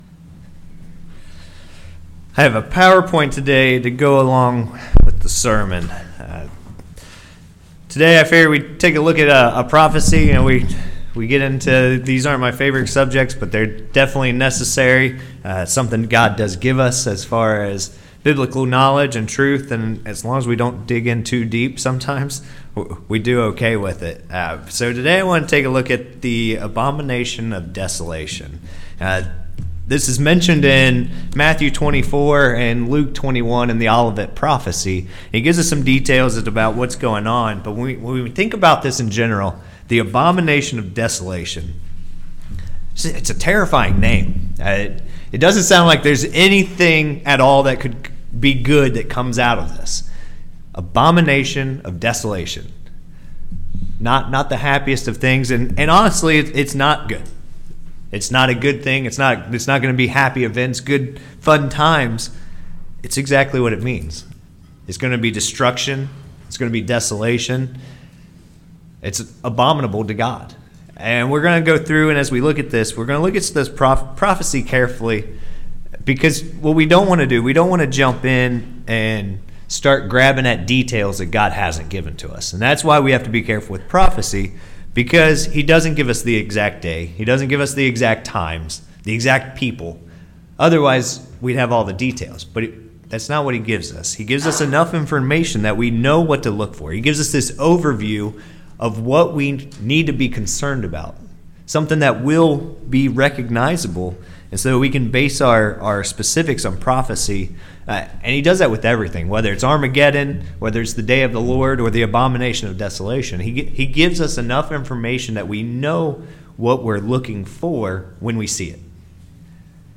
Knowing prophesy as a Christian increases our faith. This sermon covers the past (fulfilled) Abomination of Desolation.